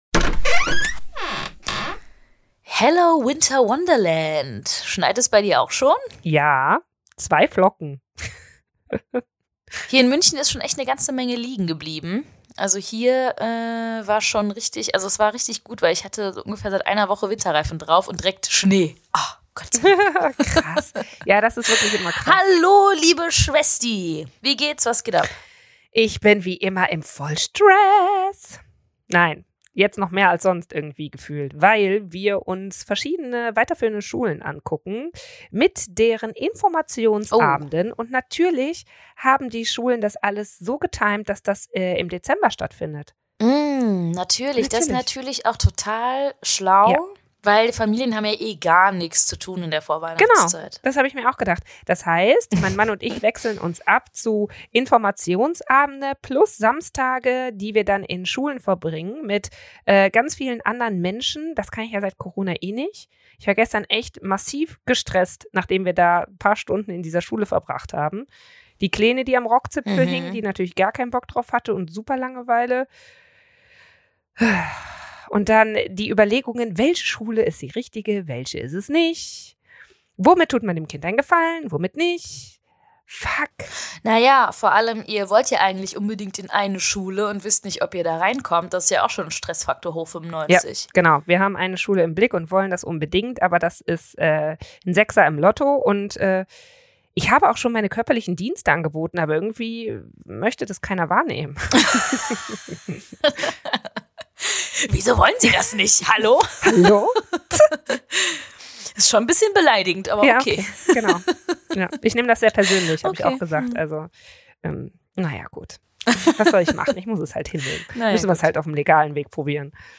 Setze ich meinem Kind Grenzen oder soll es sich so frei wie möglich entfalten? In dieser Folge sprechen die Schwestern über die verschiedenen Erziehungsstile und was die mit der Entwicklung des Kindes machen.